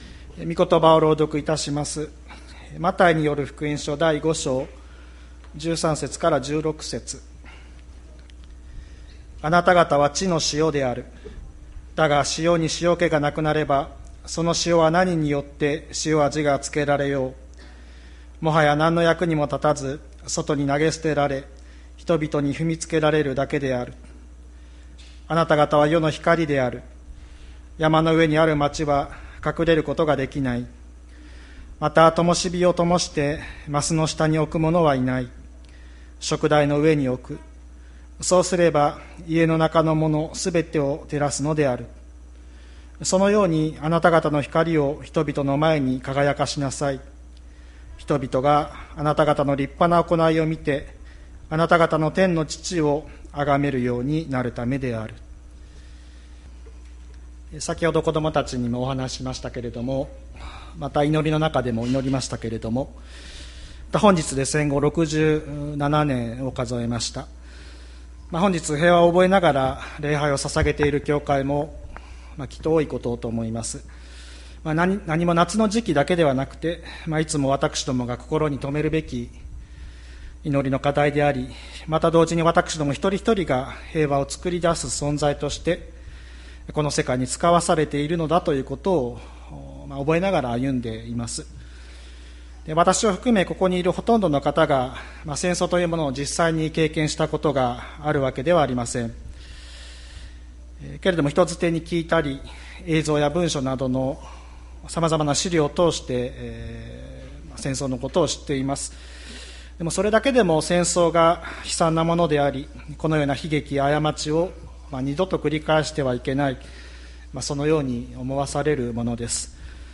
2021年08月15日朝の礼拝「わたしたちは地の塩、世の光」吹田市千里山のキリスト教会